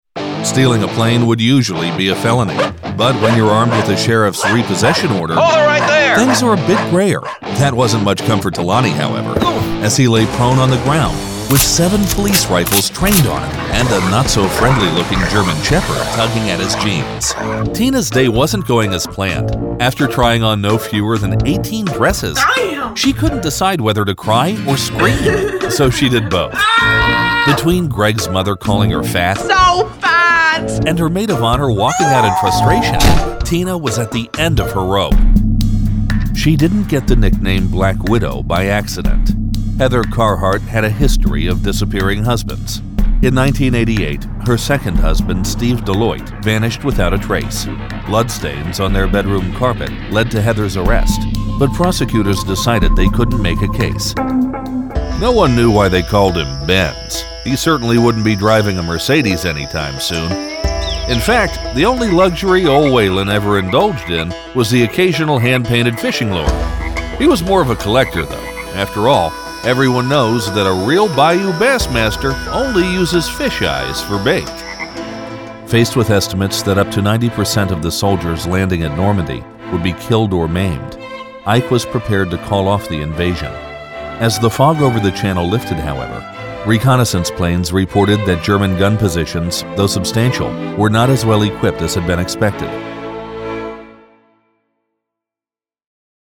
standard us
documentary